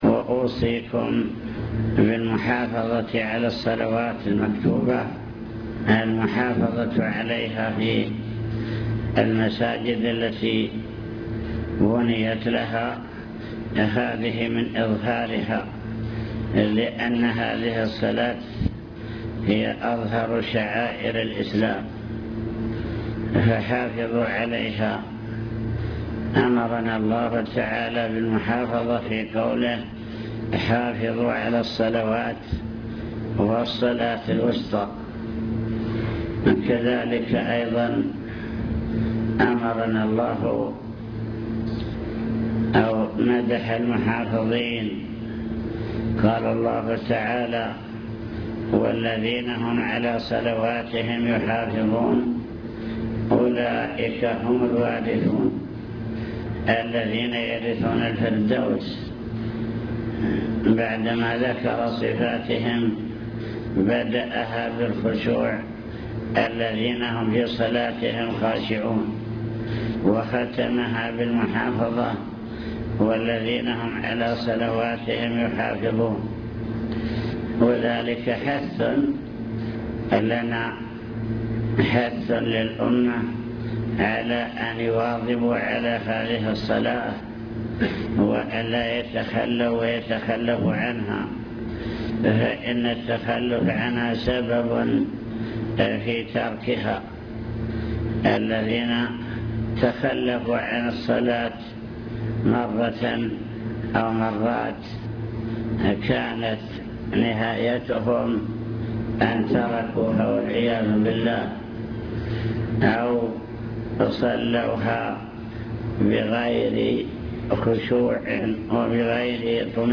المكتبة الصوتية  تسجيلات - لقاءات  كلمة للمعلمين وطلاب التحفيظ وصايا من الشيخ